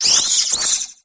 thwackey_ambient.ogg